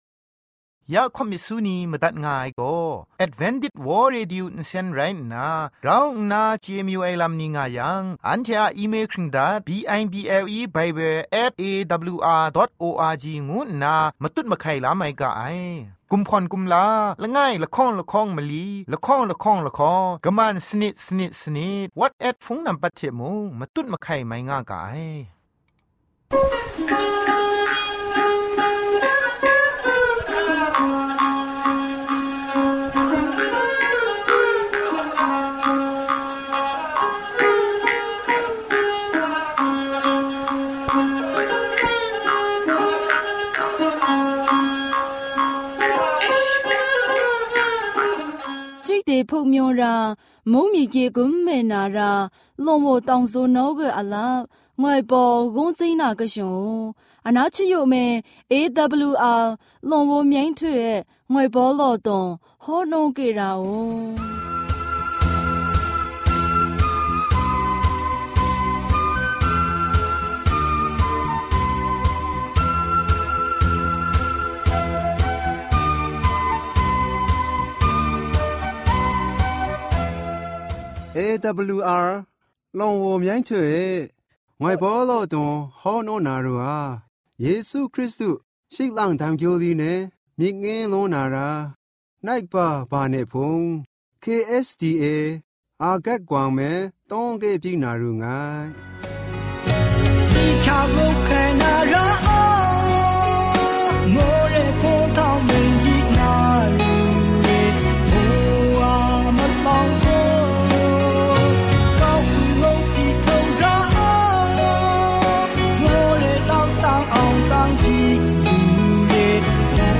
Gospel songs,health talk,sermon.